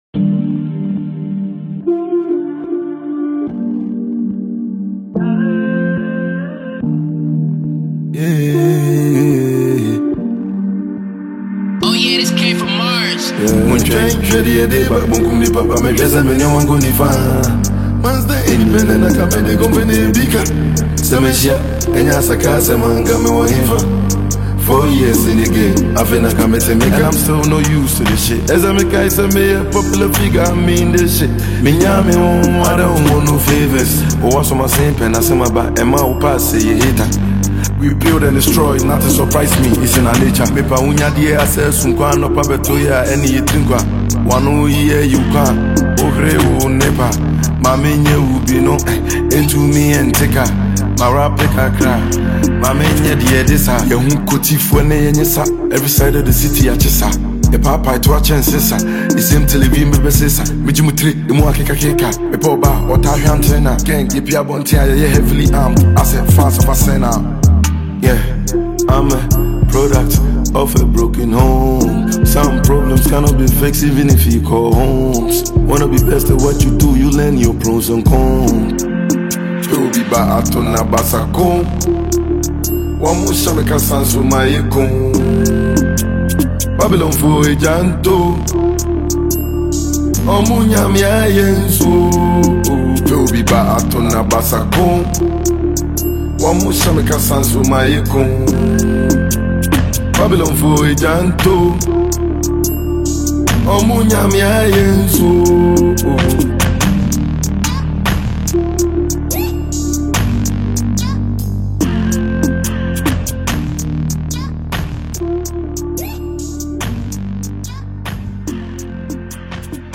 Ghanaian drill